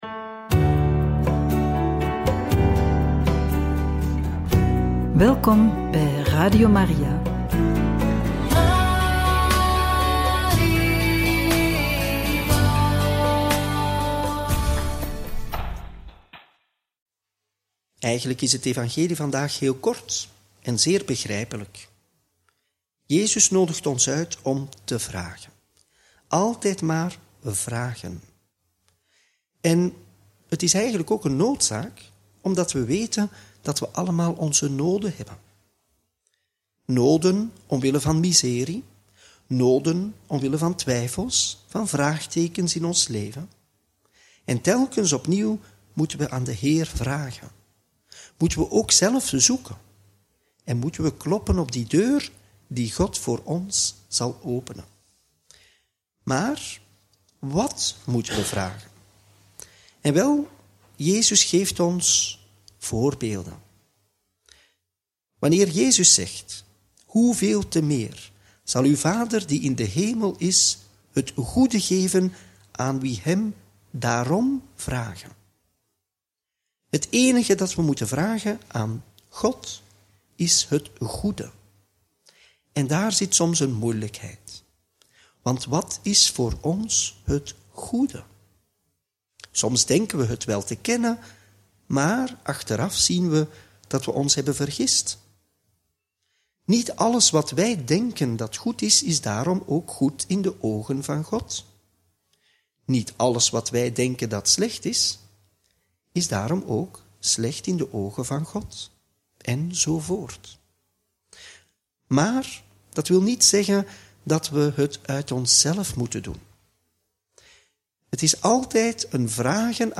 Homilie bij het Evangelie van donderdag 13 maart 2025 – Mt. 7, 7-12